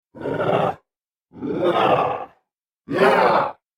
Sound Effects
Zombie Growl